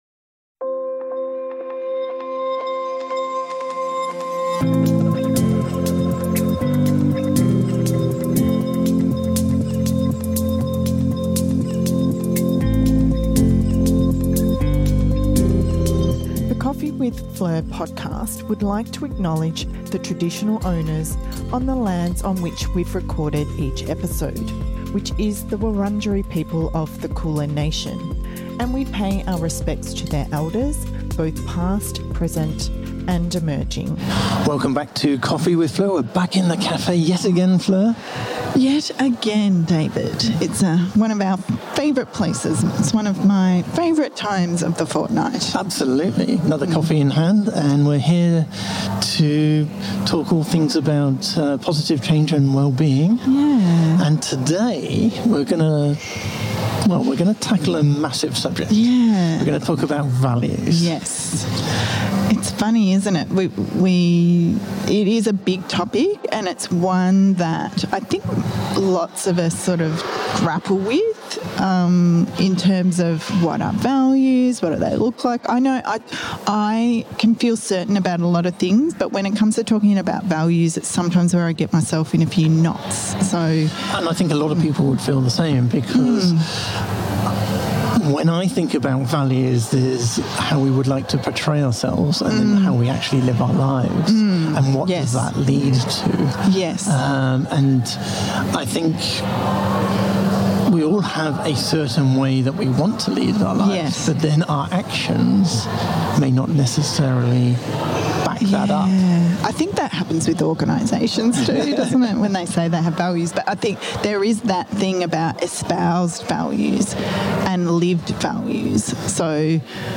Join us for this reflective conversation over a coffee as we consider what our values are and how we try to move closer to them every day.